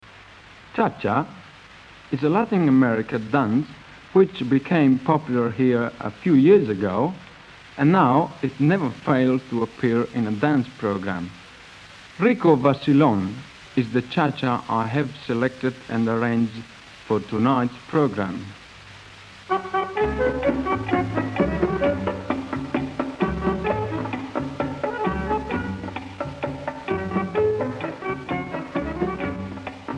accordion
cha cha